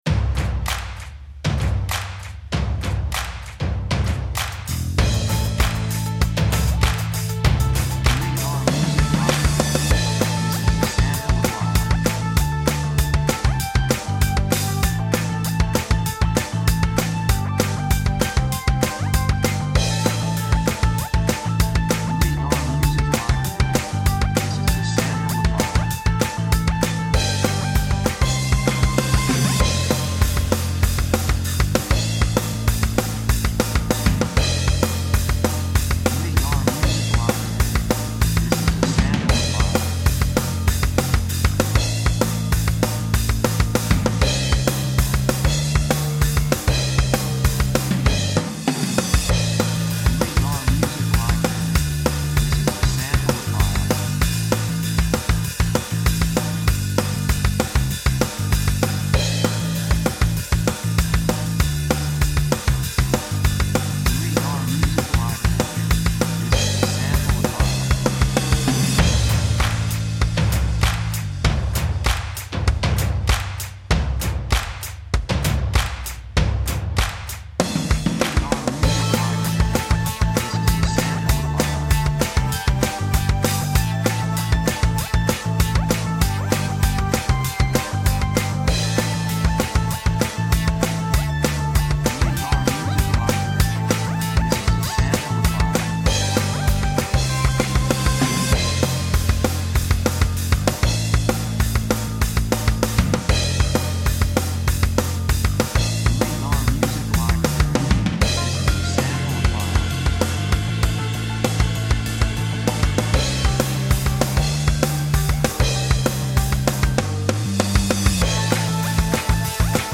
雰囲気エネルギッシュ, 壮大, 幸せ, 高揚感, 喜び
曲調ポジティブ
楽器エレキギター, 手拍子
サブジャンルポップロック, インディーロック
テンポとても速い